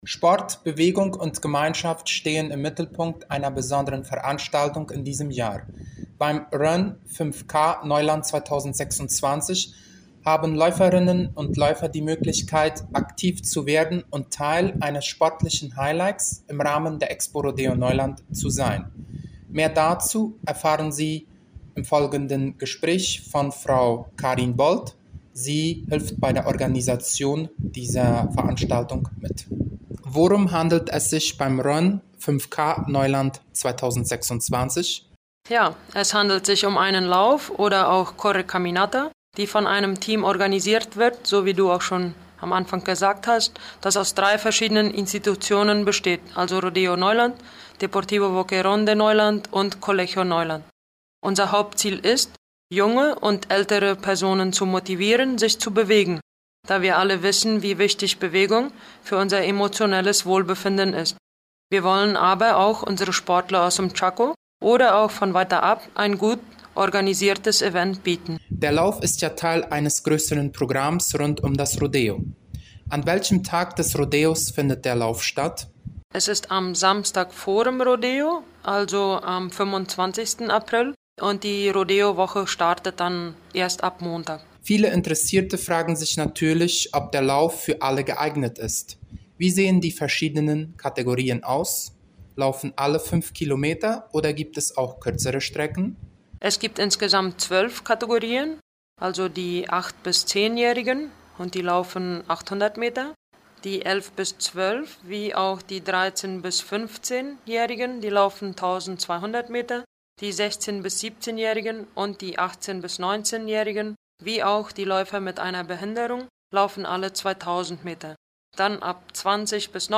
Interview zum Run 5K Neuland